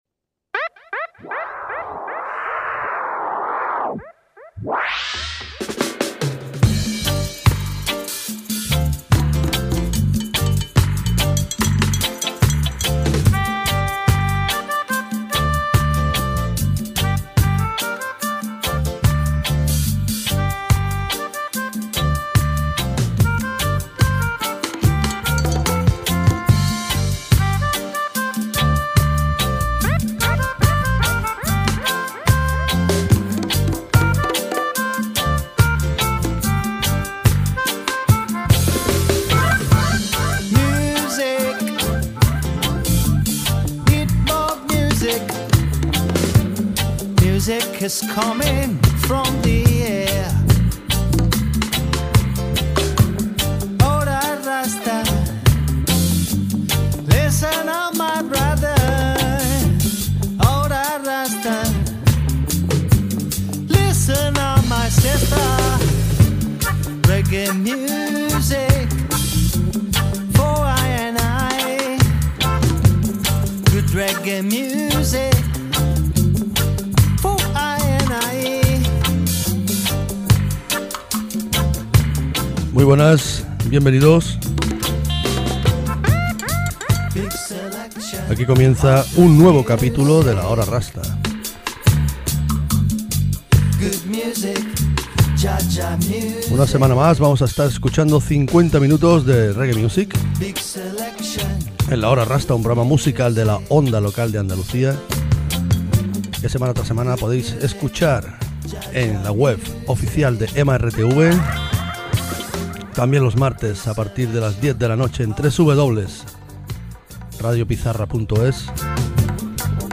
This week strictly uk dub vibes style.